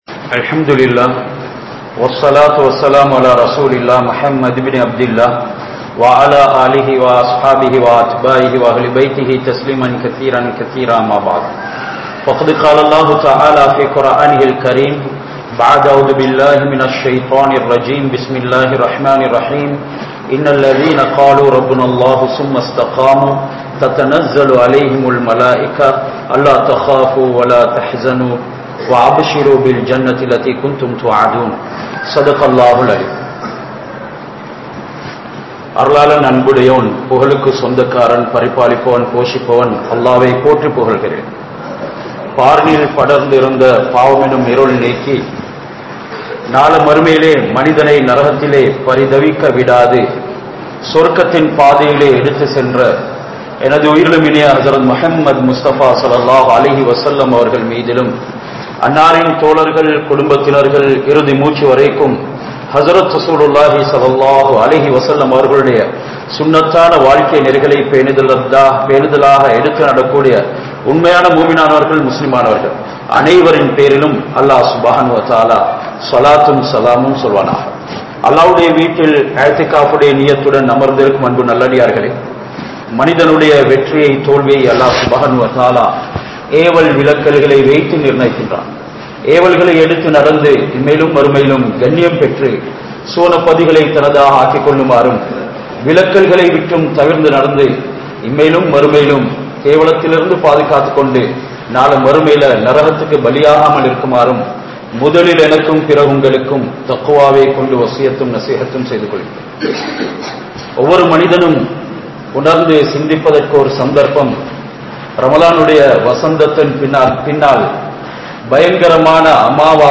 Ramalaanudan Pallivaasalhalukku Priya Vidai Koduththavarhal (ரமழானுடன் பள்ளிவாசல்களுக்கு பிரியாவிடை கொடுத்தவர்கள்) | Audio Bayans | All Ceylon Muslim Youth Community | Addalaichenai
Majmaulkareeb Jumuah Masjith